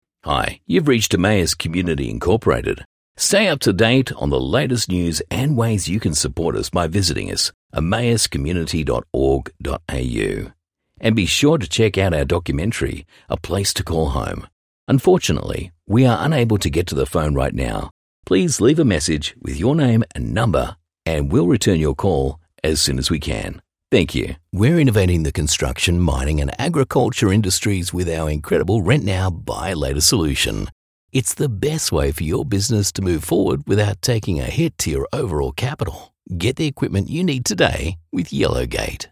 Englisch (Australisch)
Glaubhaft
Konversation
Natürlich